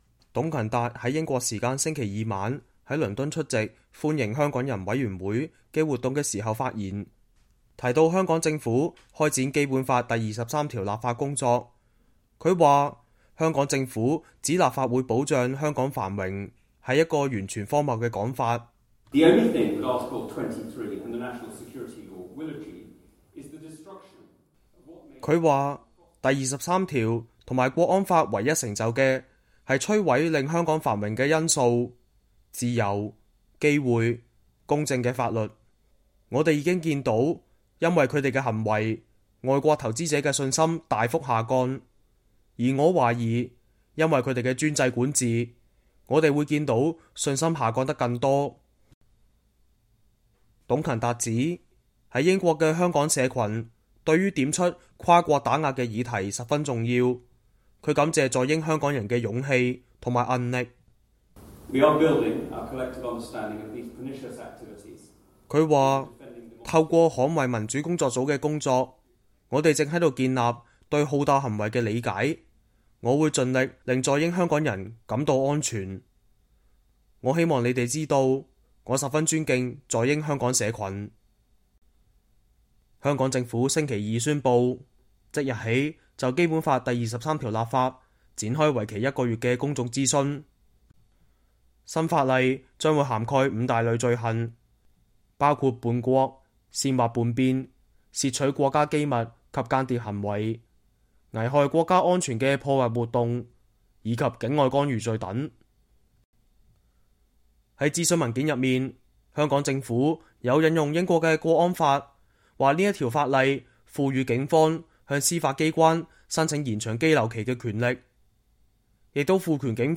董勤達英國時間星期二晚在倫敦出席“歡迎香港人委員會”（Welcoming Committee for HongKongers）的活動時發言，提到香港政府開展《基本法》第23條立法工作，他說香港政府指立法會保障香港繁榮是一個“完全荒謬”的說法。